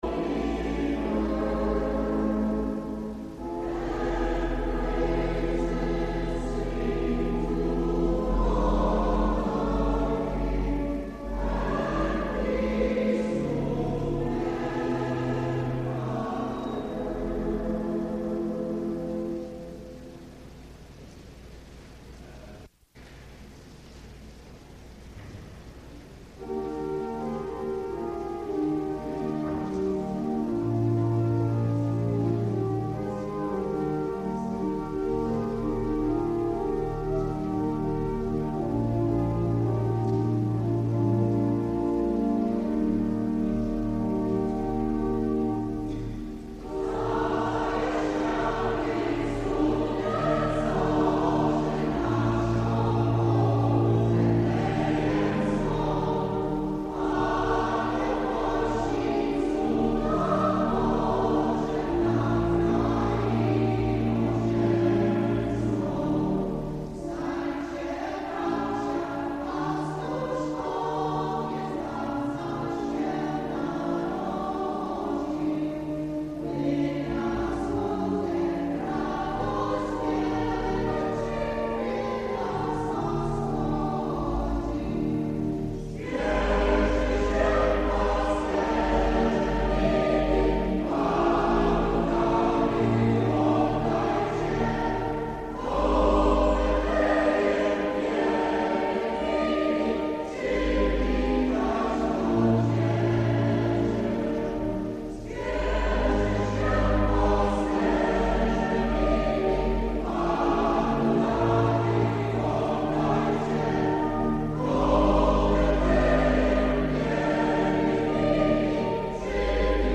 Audio recording of the St Stanislaus Choir of Chicopee, Massachusetts at Christmas 1984